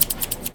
R - Foley 191.wav